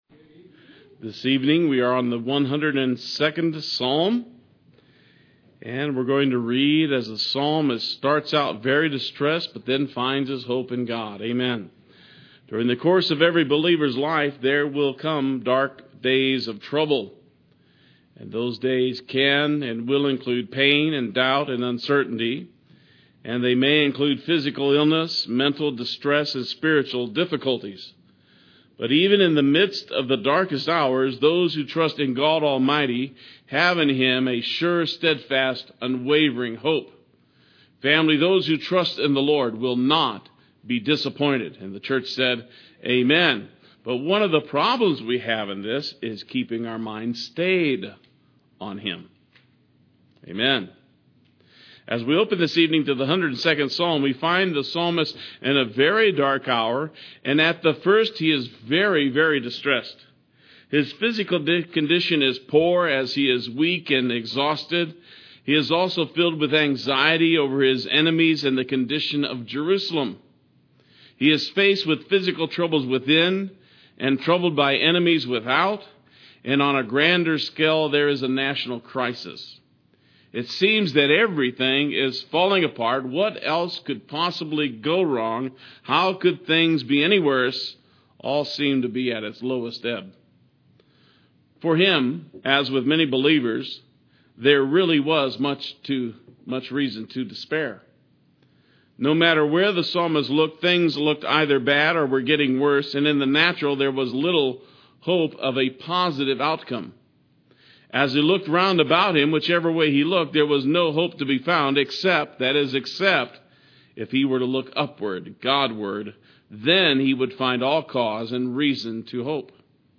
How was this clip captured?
Psalm 102 Wednesday Worship